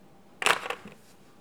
bruit-page_02.wav